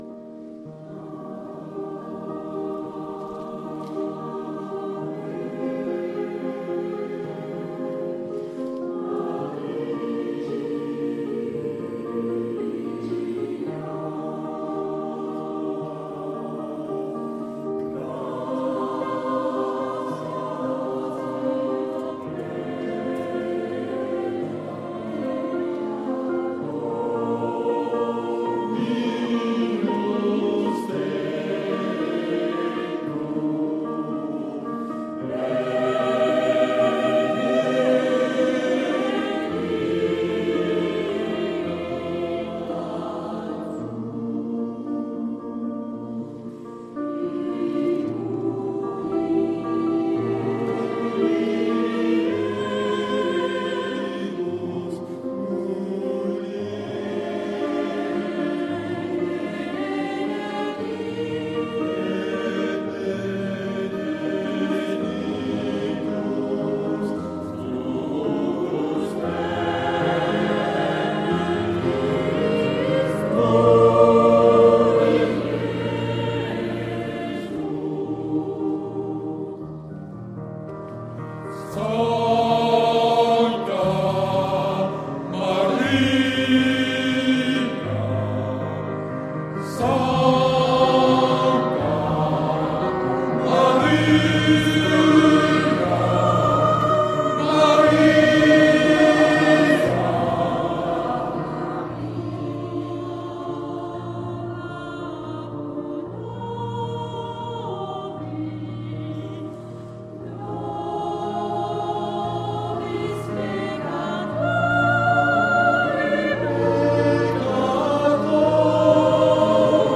MUSIQUE SACREE